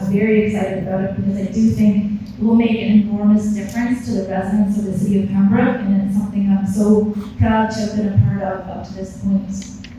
At a press conference held at Algonquin College